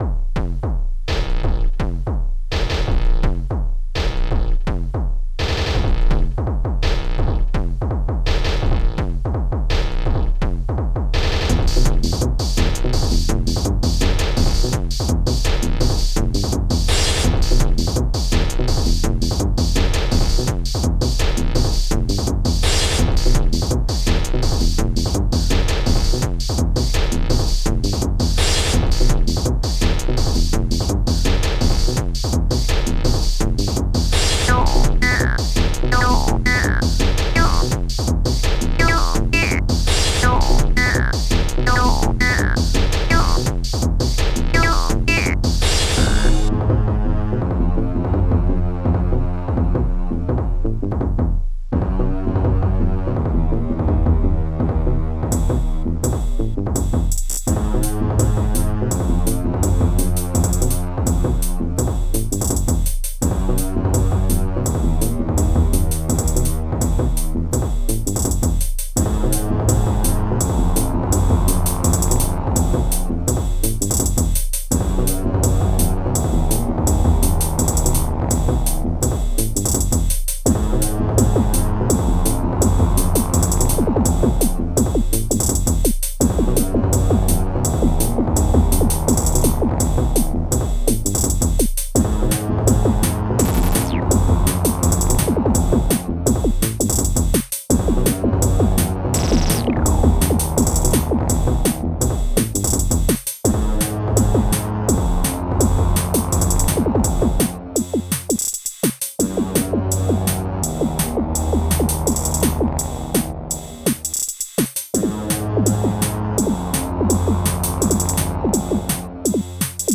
20-23... my 606 & kpr77
playable at tempos:125